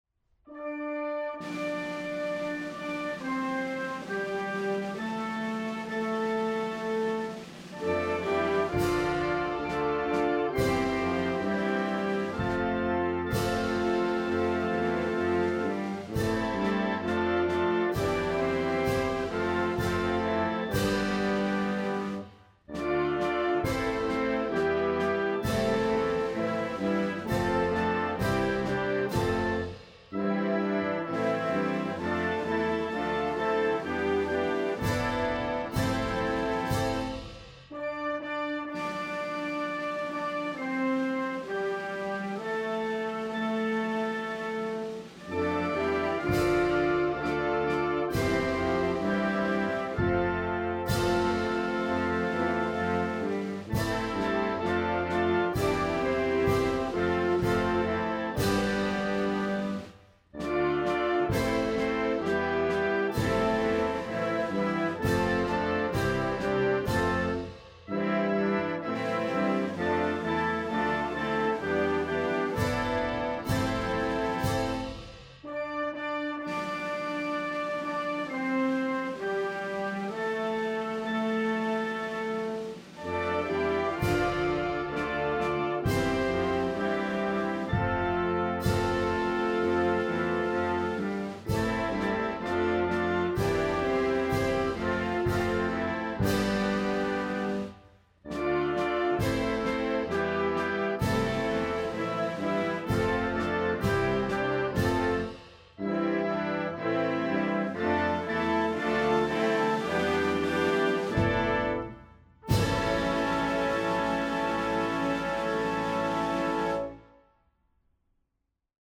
Hymne